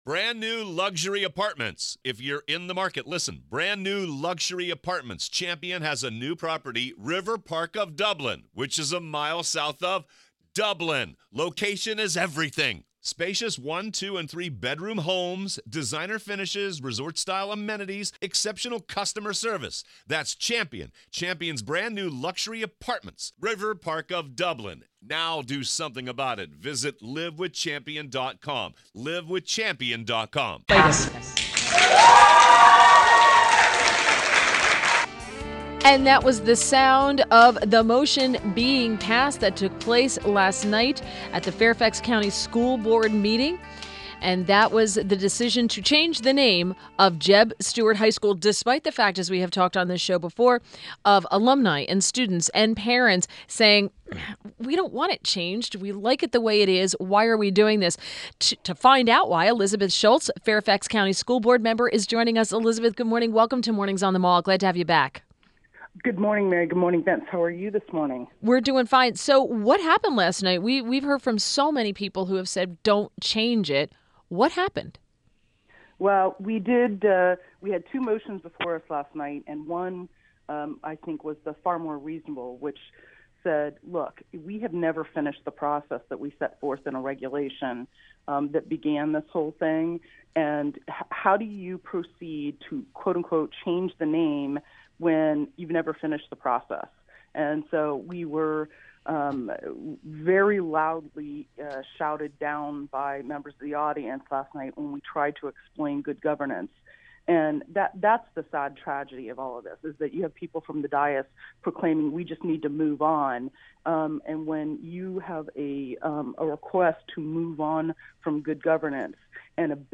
INTERVIEW – ELIZABETH SCHULTZ – Fairfax County School Board Member